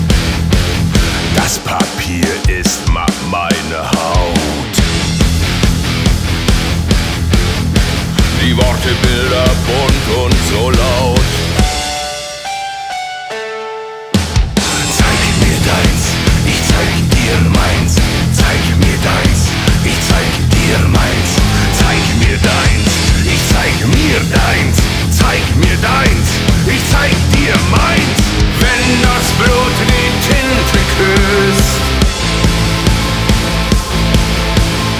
Рингтоны
Жанр: Рок